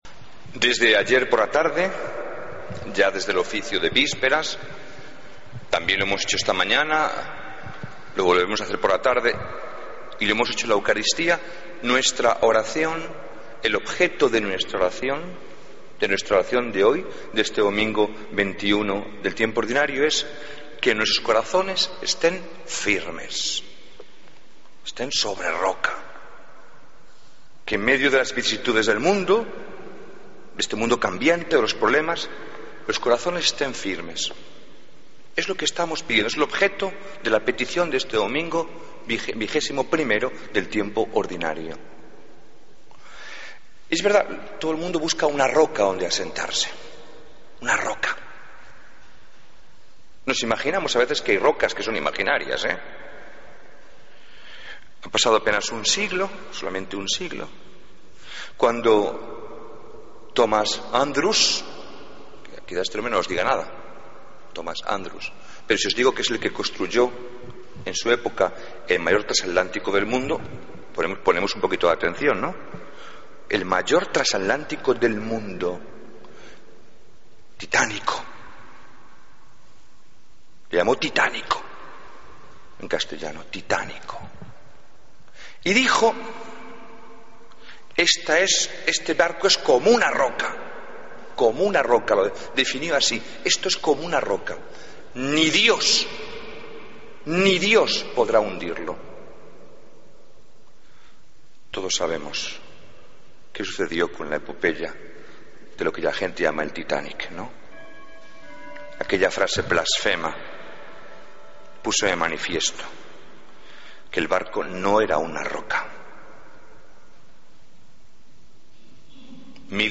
Homilía del 24 de agosto de 2014